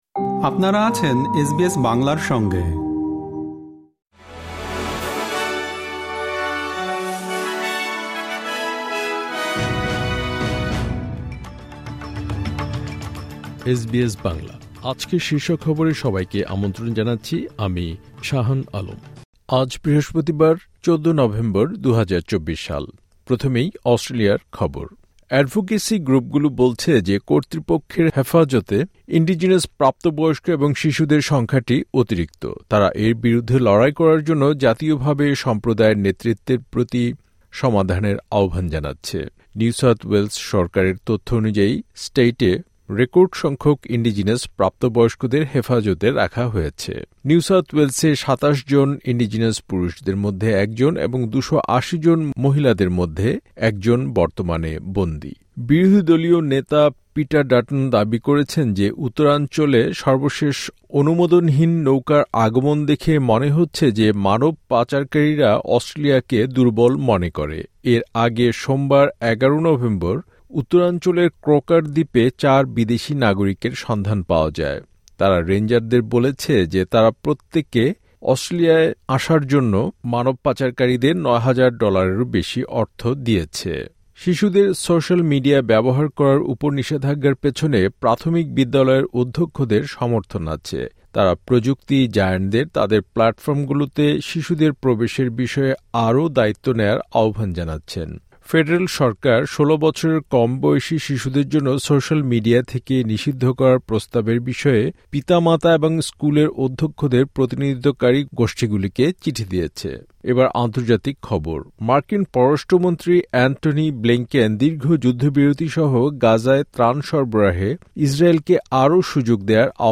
এসবিএস বাংলা শীর্ষ খবর: ১৪ নভেম্বর, ২০২৪